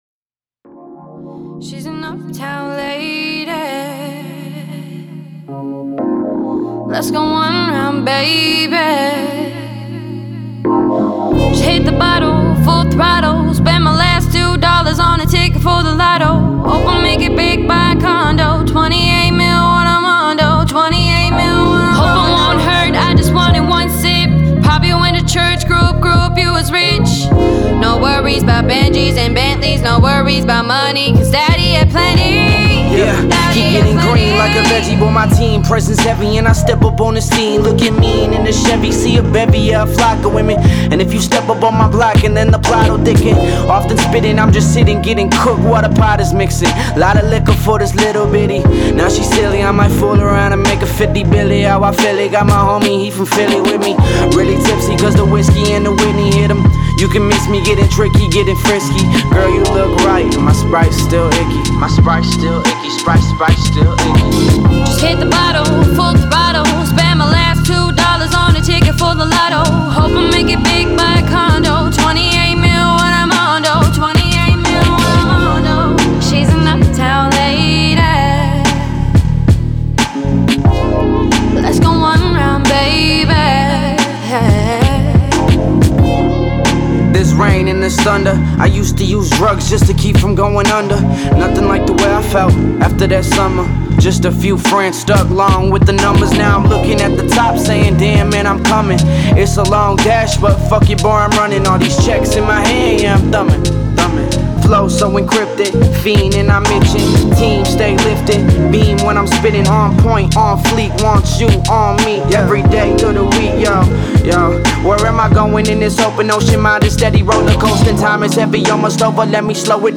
(Mastered)
Hip/Hop